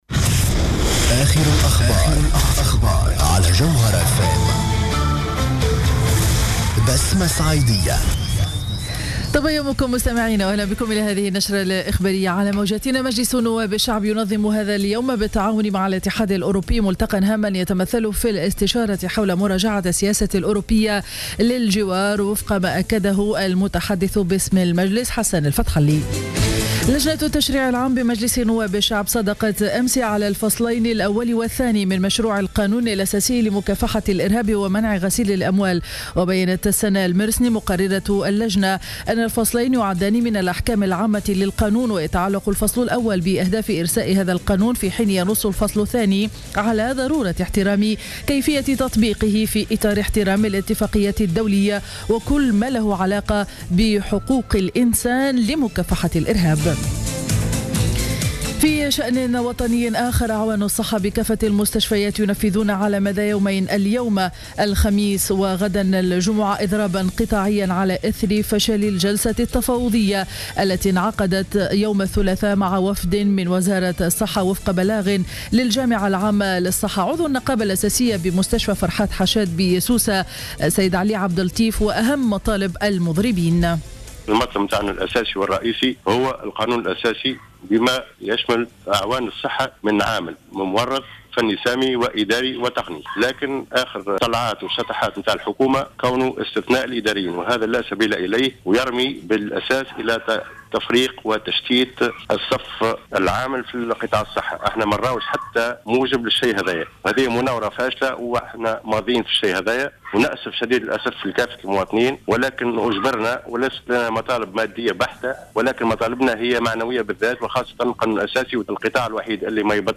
نشرة أخبار السابعة صباحا ليوم الخميس 11 جوان 2015